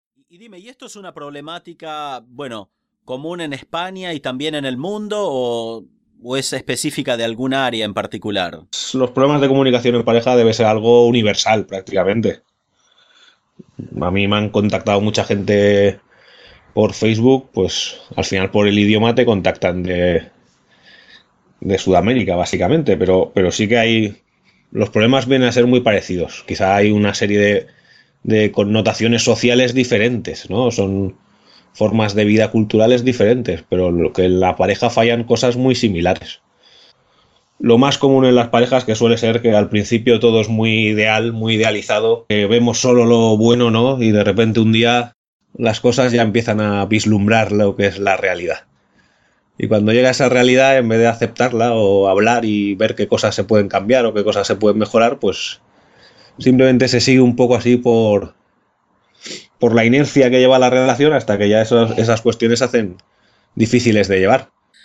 Interview
Recording: 0062 Level: Advanced Spanish Variety: Spanish from Spain